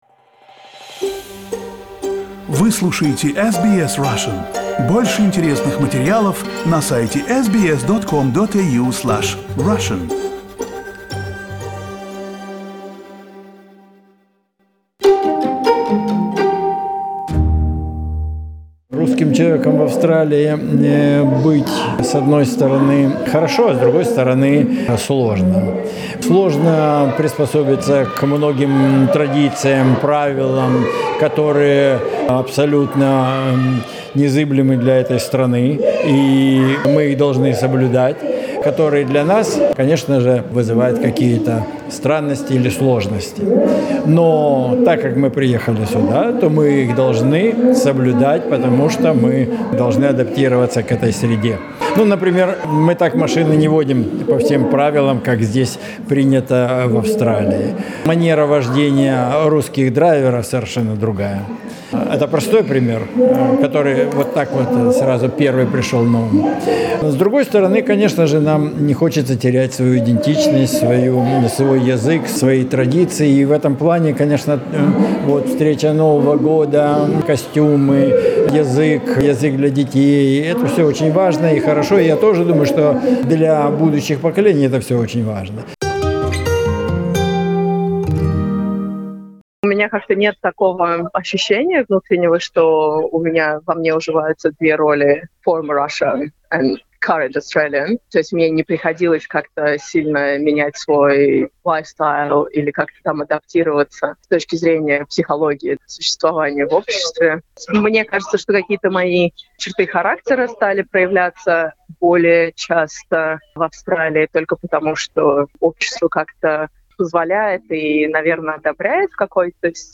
Vox pop: What does it mean to be a Russian-Australian?